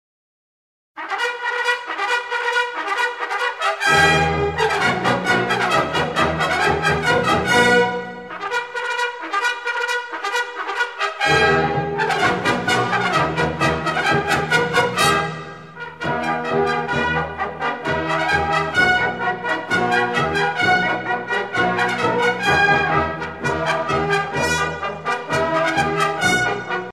Fonction d'après l'analyste gestuel : à marcher
Usage d'après l'analyste circonstance : militaire
Pièce musicale éditée